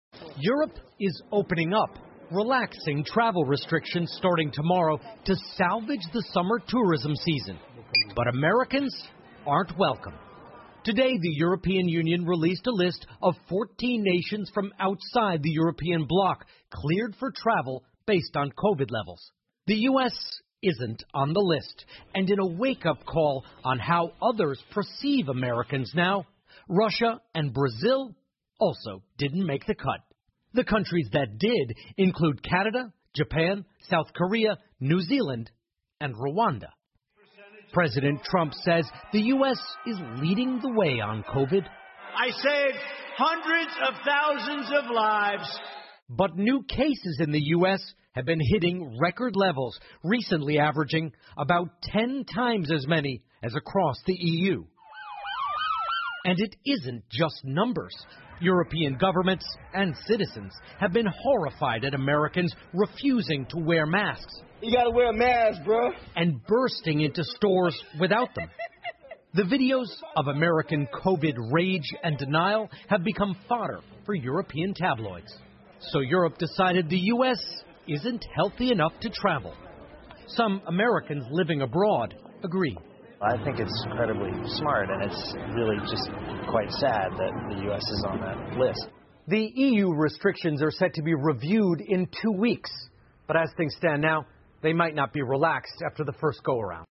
NBC晚间新闻 欧盟禁止美国人入境 听力文件下载—在线英语听力室